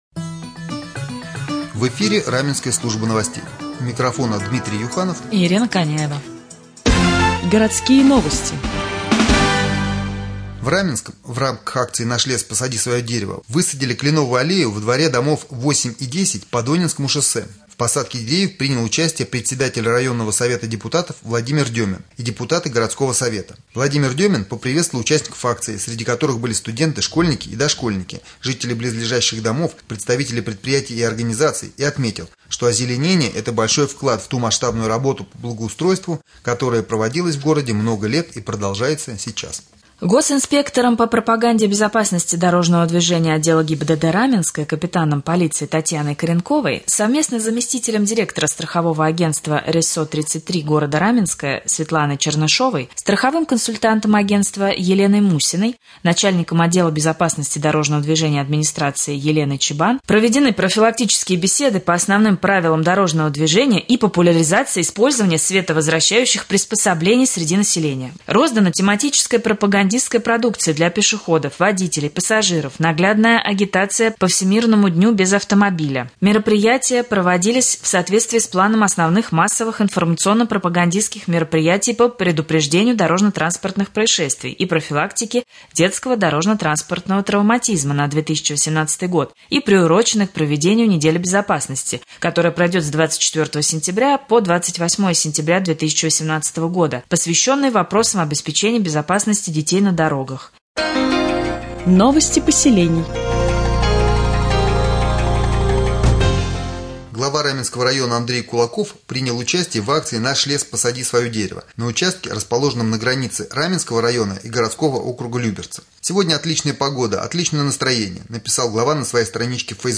1.Новости Подмосковья и Раменского района